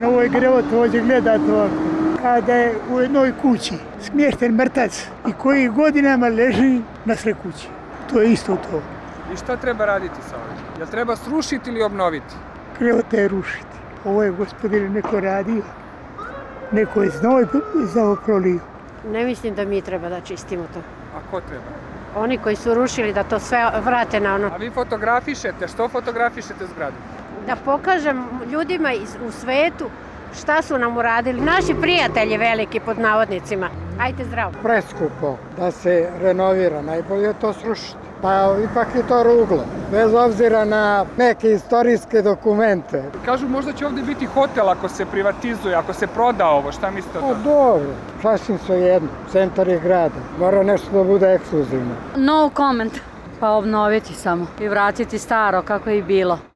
Beograđani, koji su sa radoznalo zastajkivali da vide otkud novinari kod Generalštaba, rekli su za RSE da je bilo krajnje vreme da se nešto preduzme.
Beograđani o raščišćavanju Generalštaba